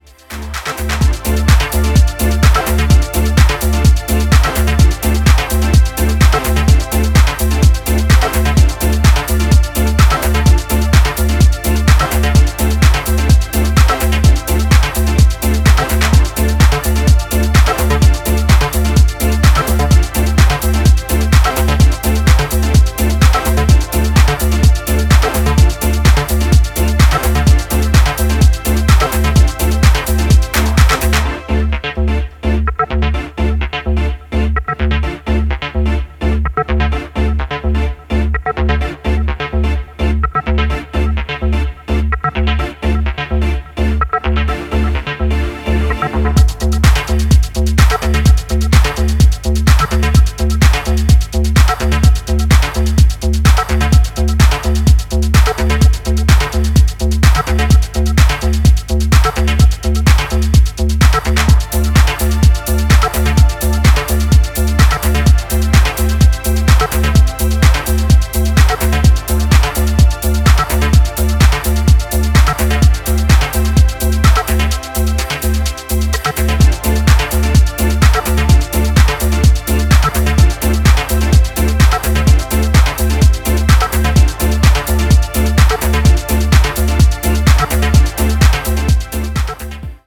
バウンシーなボトムと残響感のあるシンセ、高揚を煽るコードを絡ませクライマックスまで登り詰める